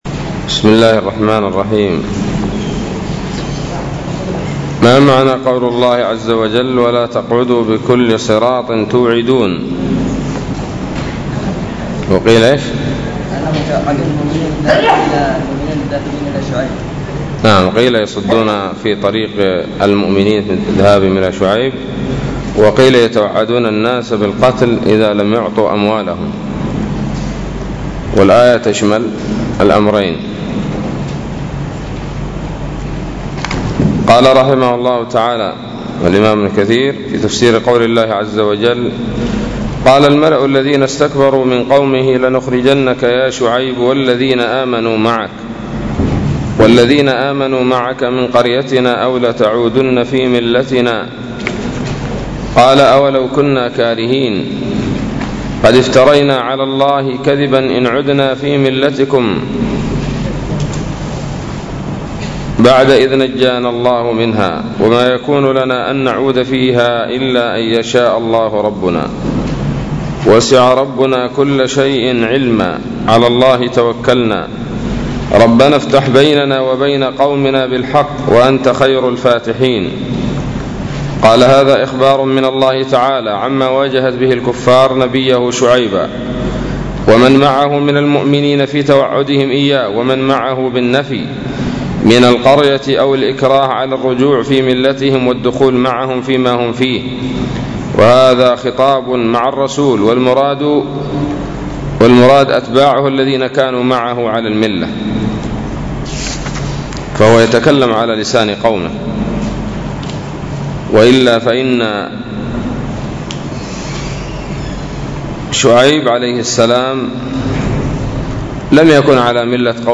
الدرس الخامس والثلاثون من سورة الأعراف من تفسير ابن كثير رحمه الله تعالى